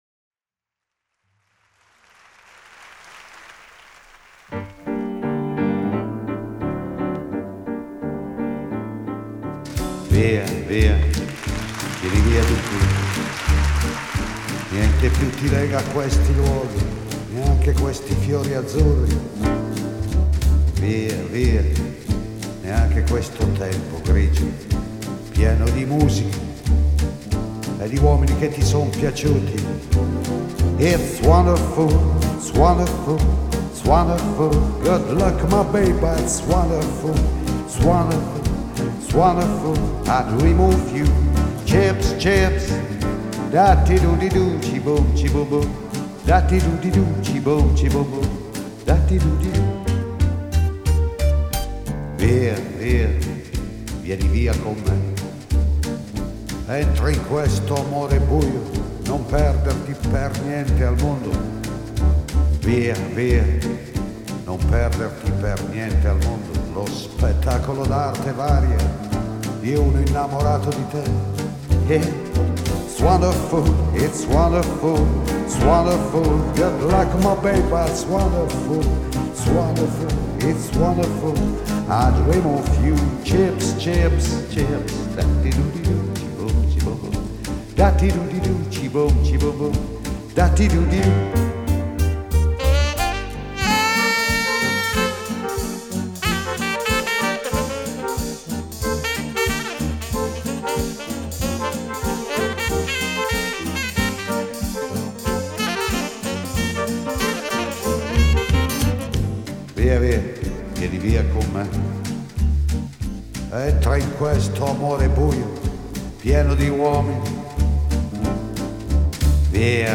Ни одного слова не понимаю, но какой голос у мужчины.